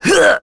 Roman-Vox_Attack2.wav